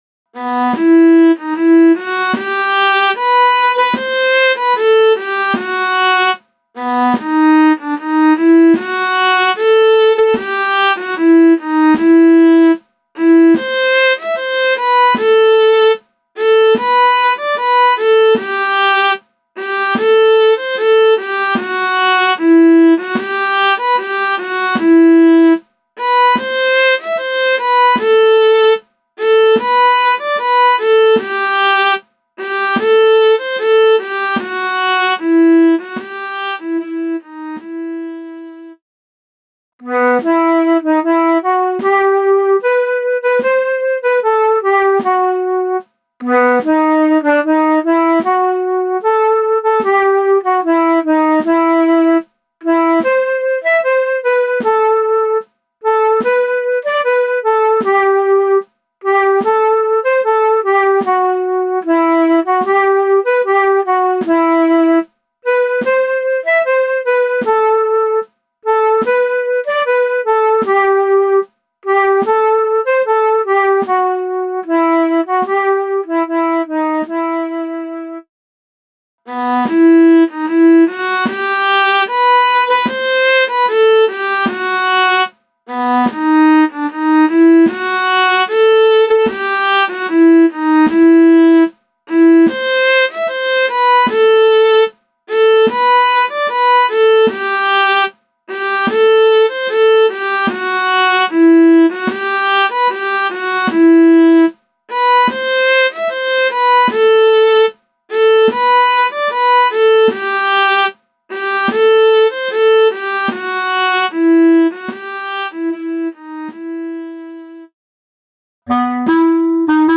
MIDI - 1-stimmig
midi_fahrtenbrueder_1-stimmig_320.mp3